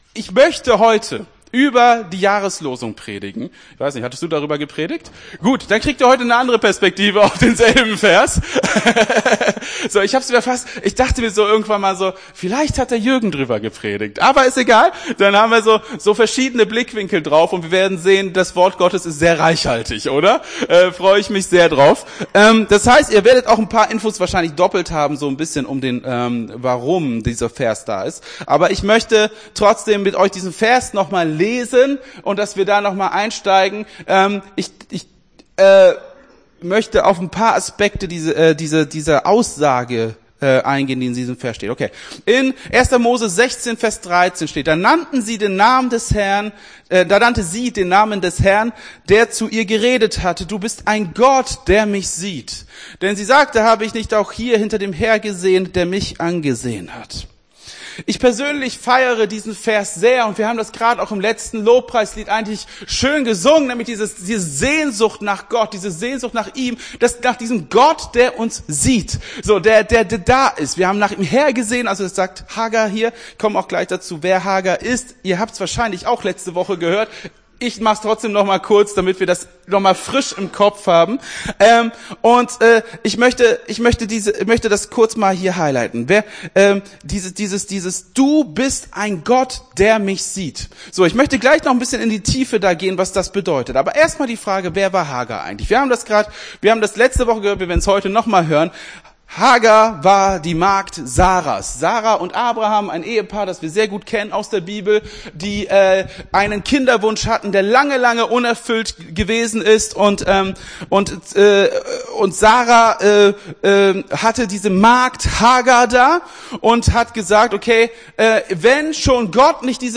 Gottesdienst 15.01.23 - FCG Hagen